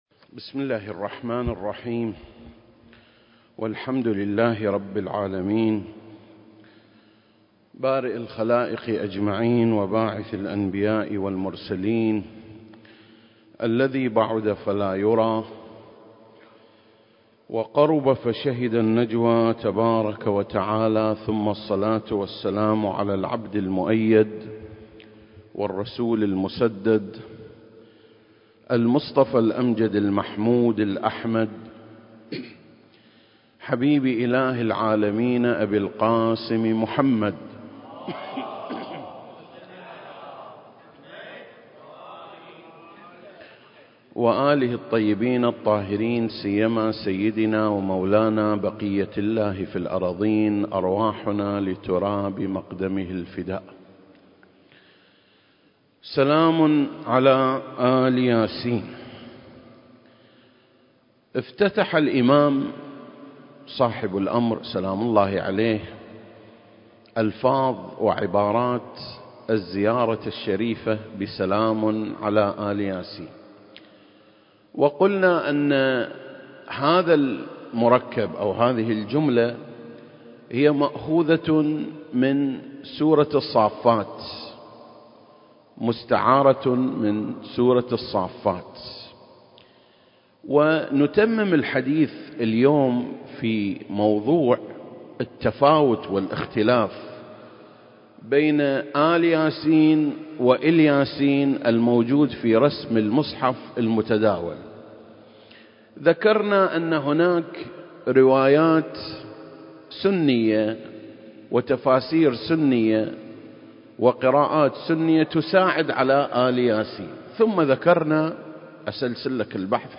سلسلة: شرح زيارة آل ياسين (22) - آل ياسين (3) المكان: مسجد مقامس - الكويت التاريخ: 2021